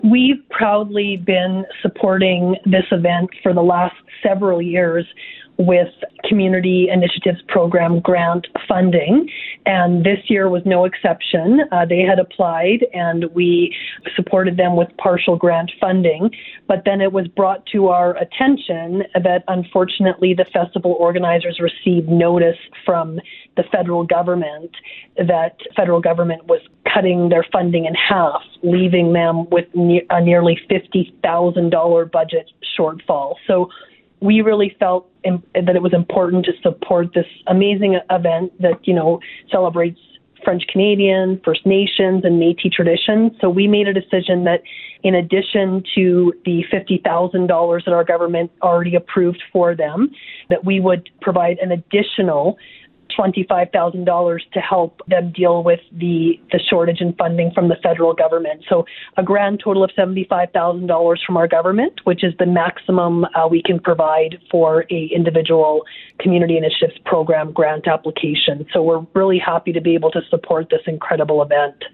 Tanya Fir, Minister of Arts, Culture, and status of women spoke with Windspeaker Radio Network about the funding contribution towards the Flying Canoe event.